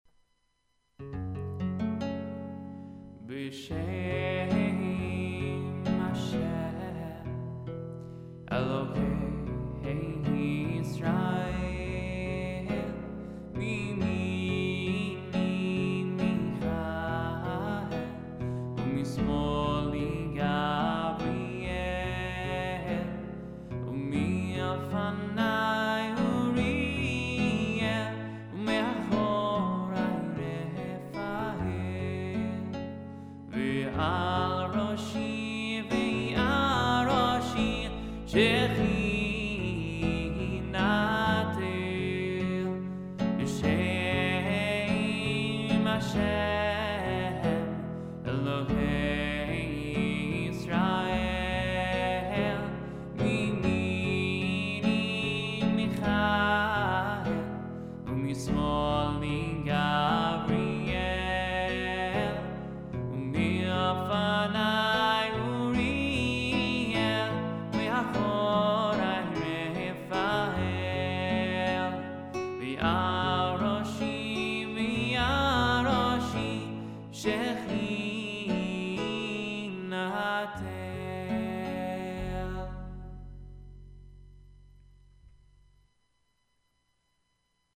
Lullabies